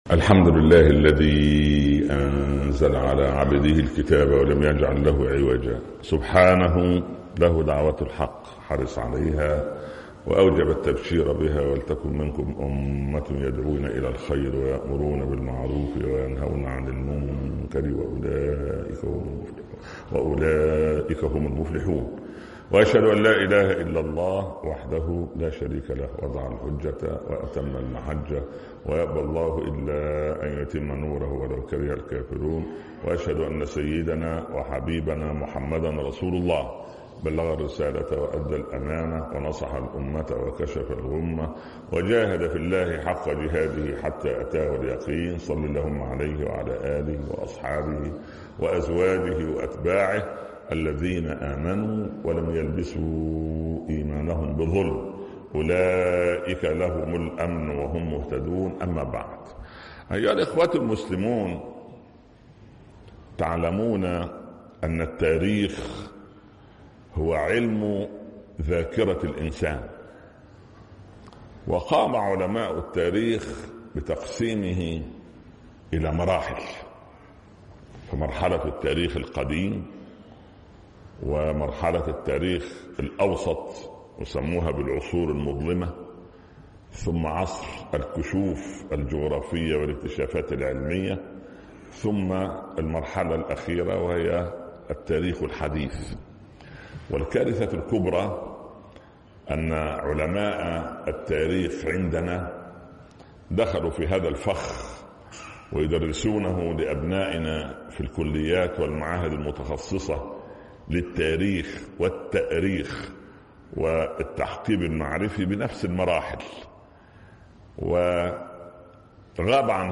تاريخ الحقائق - خطب الجمعة